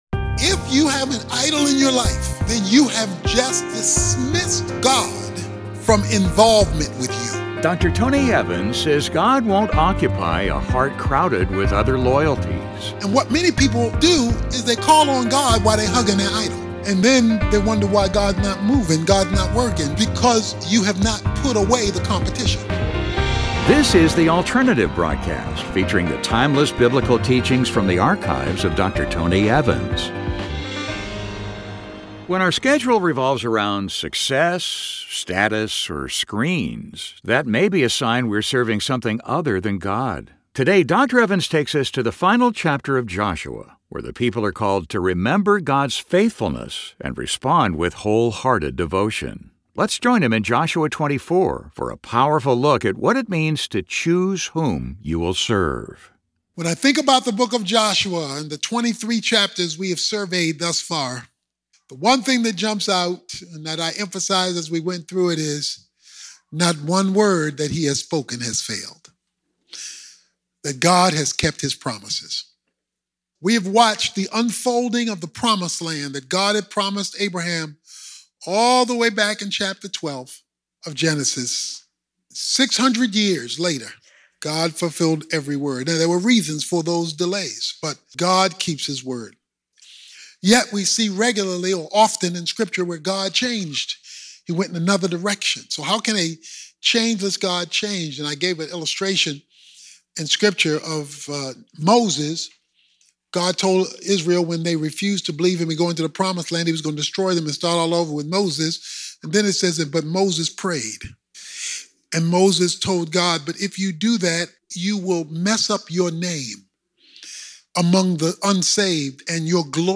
In this message, Dr. Tony Evans challenges us with a defining question from the book of Joshua: Who will you serve? Discover how to re-center your priorities on whatÂ matters most.